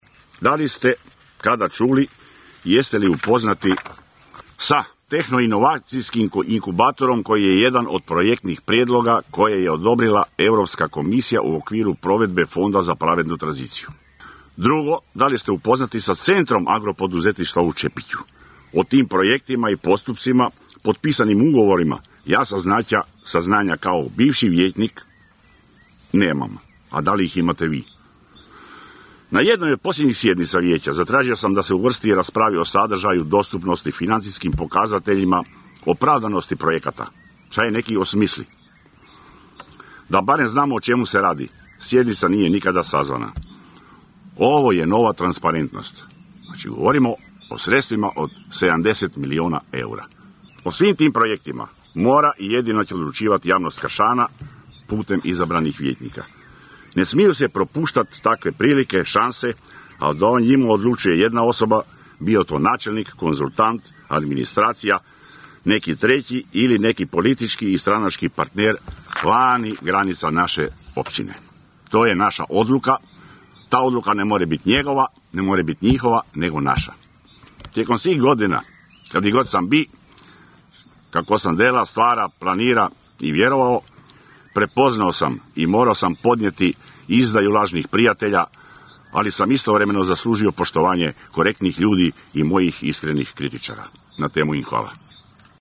"Vraćam se s ekipom, da završim ostavljeno u svibnju 2021. godine", poručio je jučer na predstavljanju dijela programa nezavisni kandidat za općinskog načelnika Kršana i nositelj kandidacijske liste za Općinsko vijeće, bivši načelnik, Valdi Runko
ton – Valdi Runko 1), zaključio je nezavisni kandidat za načelnika Općine Kršan i nositelj kandidacijske liste za Općinsko vijeće Valdi Runko.